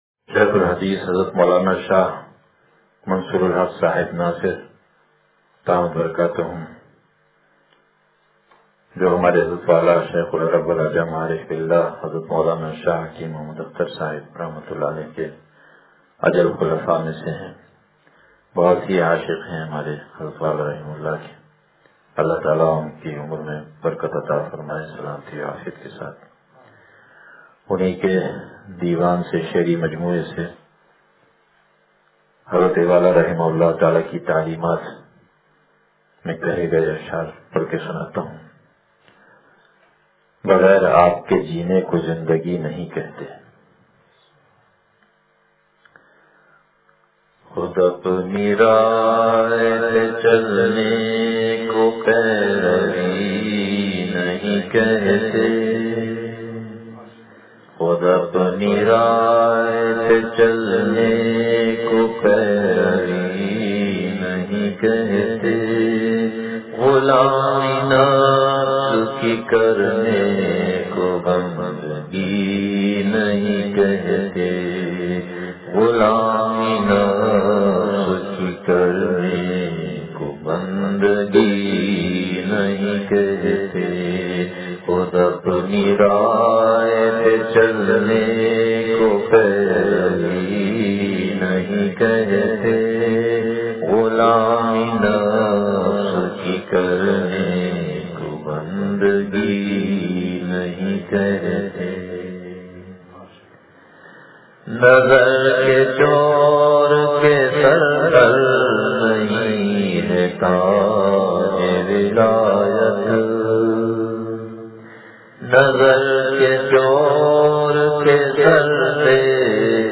دیوانِ ناصر سے اشعار – بغیر آپ کے جینے کو زندگی نہیں کہتے – حیاۃ المسلمین – مجلس بروز بدھ